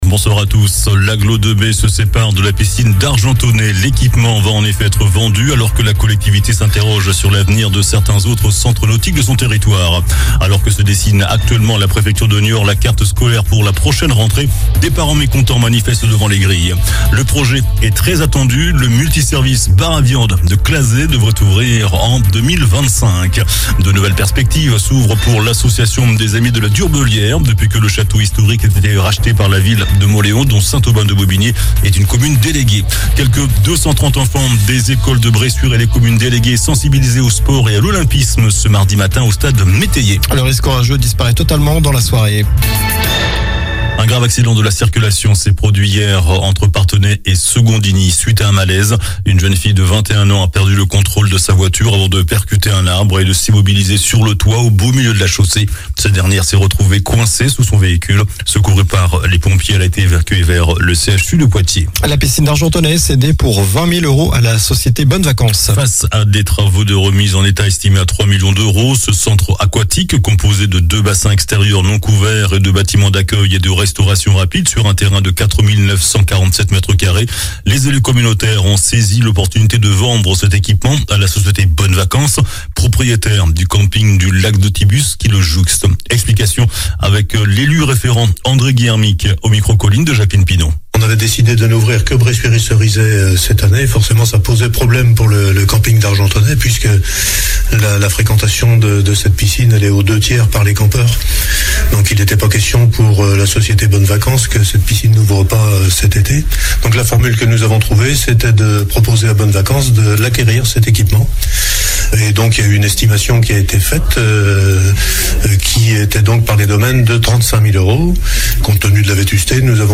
Journal du mardi 20 juin (soir)